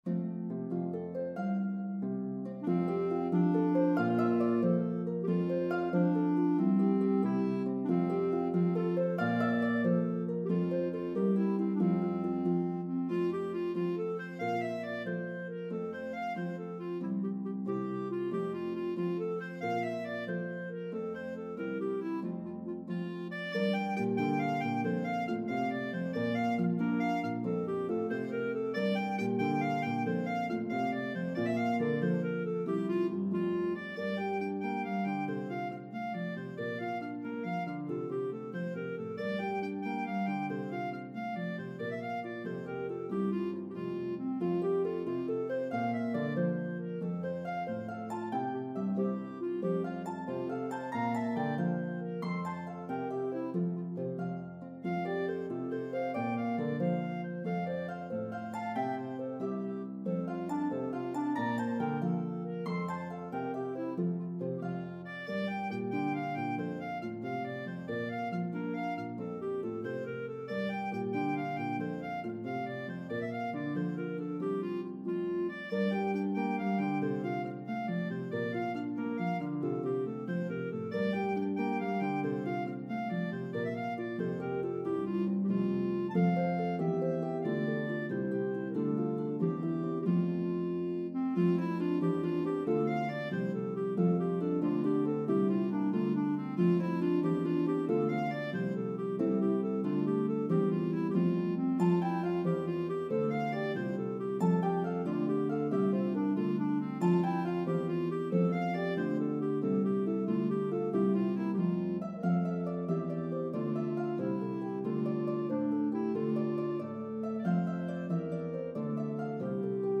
medley of two joyful, upbeat Irish Jigs